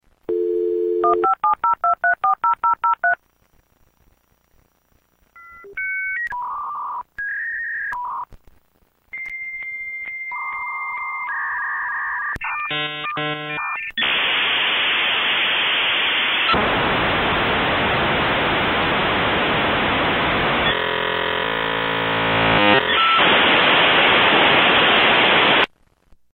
Qual é o som de um Modem?
No tempo em que se usava um modem dial-up, o utilizador podia ouvir um modem de conexão à Internet, tal como, no ficheiro de som abaixo. Cada vez que alguém queria conectar-se à Internet este é o ruído que o seu Modem faria.
Neste ficheiro de som, podemos ouvir o modem a marcar um número de telefone e, em seguida, comunicar com o outro modem através da linha telefónica. O ruído alto após a marcação do número de telefone é o modem a estabelecer uma conexão. Uma vez que a conexão é estabelecida o modem fica em silêncio.
modem.mp3